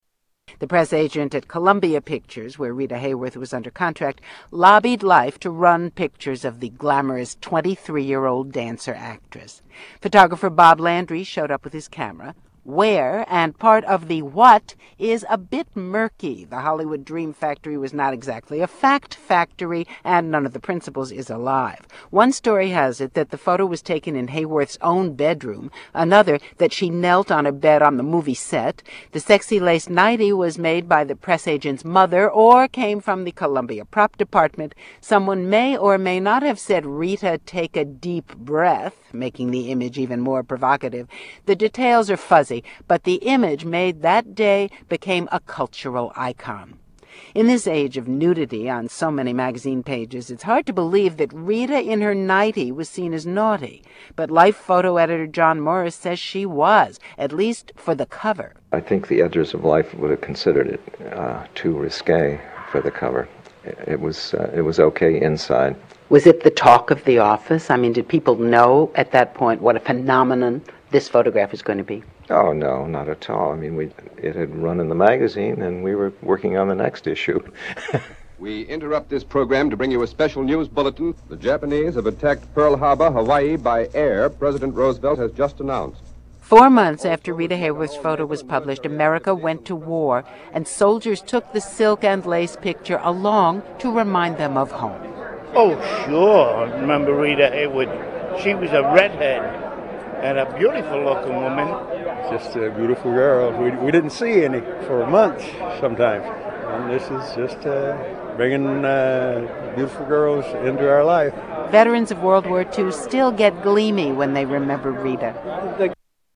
NPR report on Rita Hayworth 2